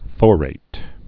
(fôrāt)